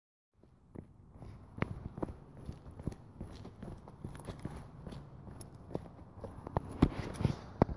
描述：单声道录音，用软底鞋在水泥地上行走。修剪成单一的脚步声。
标签： 混凝土 脚步声 FX 步骤 行走